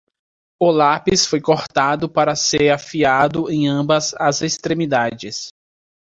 Leer más Significado (Inglés) Det Pron ambos Det Pron Leer más Conceptos ambas Traducciones ambdues Frecuencia B2 Pronunciado como (IPA) /ˈɐ̃.bɐs/ Marcar esto como favorito Mejora tu pronunciación Notes Sign in to write sticky notes